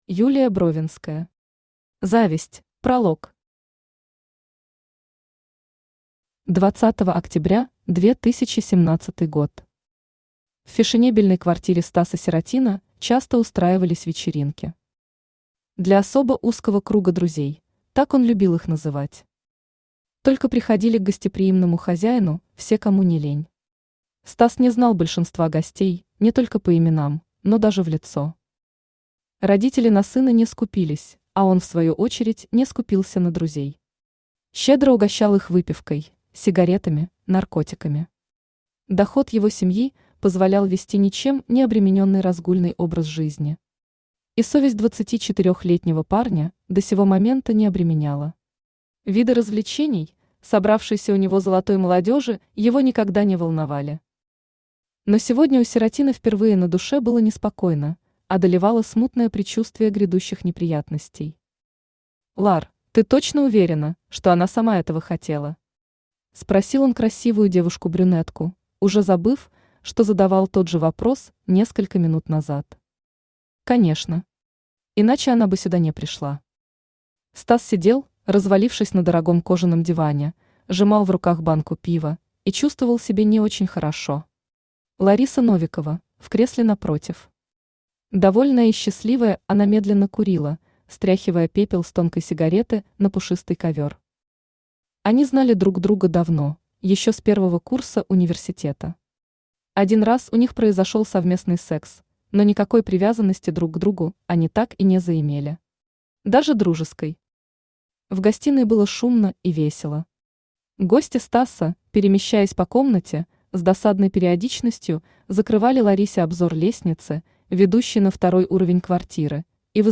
Аудиокнига Зависть | Библиотека аудиокниг
Aудиокнига Зависть Автор Юлия Бровинская Читает аудиокнигу Авточтец ЛитРес. Прослушать и бесплатно скачать фрагмент аудиокниги